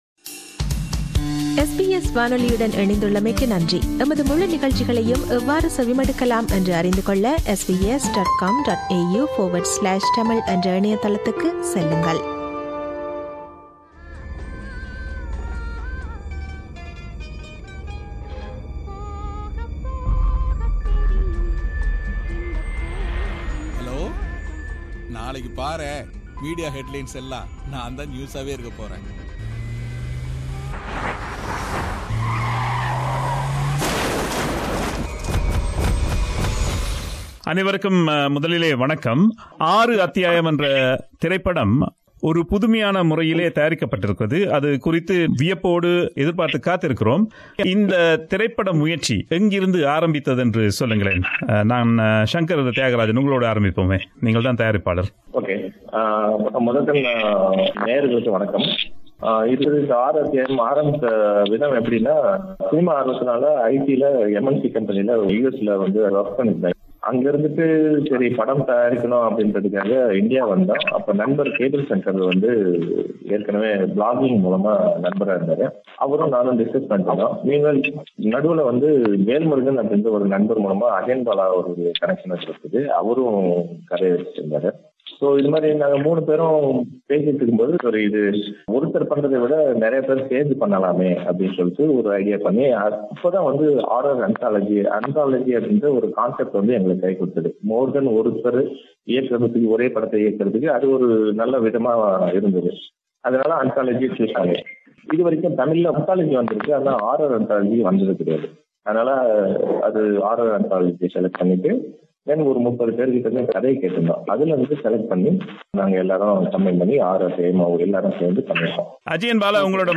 talked to all six directors at the same time, the day before the movie was released to the general audience.